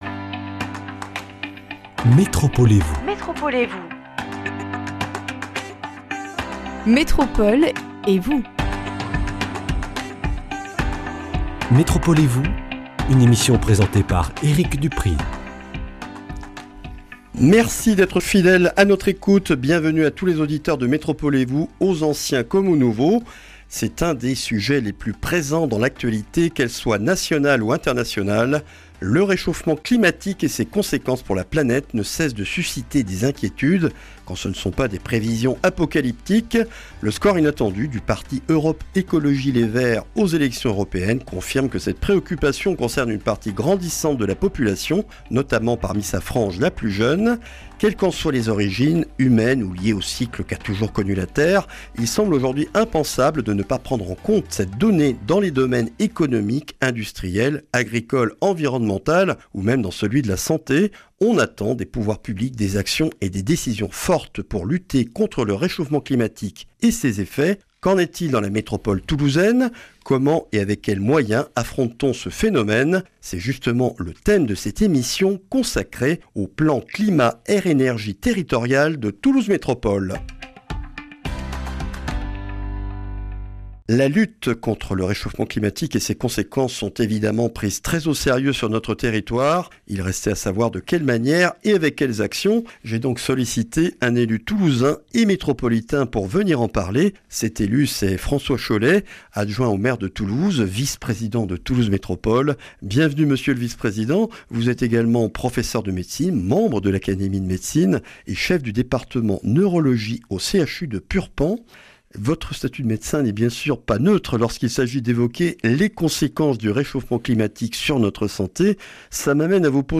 Le réchauffement climatique et ses conséquences sont de plus en plus au coeur des préoccupations des citoyens et des élus. François Chollet, adjoint au maire de Toulouse, vice-président de Toulouse Métropole, expose le diagnostic réalisé sur le territoire de la métropole toulousaine et les actions du Plan Climat Air Énergie Territorial (PCAET) de Toulouse Métropole.